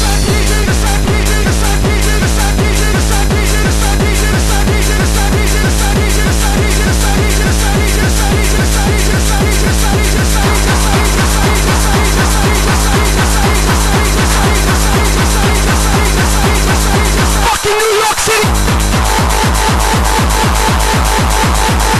스피드코어는 기본적으로 BPM 300 이상인 하드코어로 여겨진다.
스피드코어의 대표적인 곡인